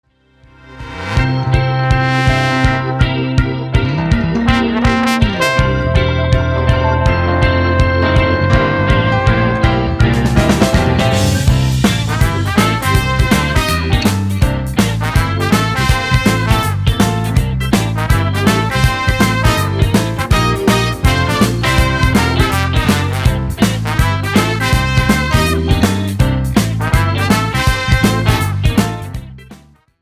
POP  (03.02)